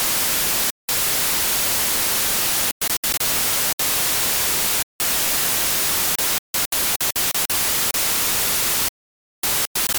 На этой странице собраны различные звуки глитч-эффектов — от резких цифровых помех до плавных искажений.
Звук Глитч для видео заставка или титры